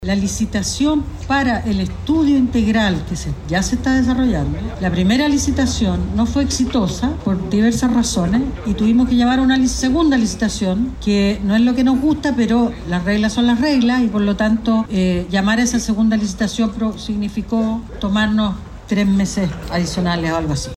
Después de realizarse este anuncio, fue la misma ministra de Obras Públicas, Jessica López, quien aseguró que el proceso de licitación del estudio integral fracasó por “diversas razones”.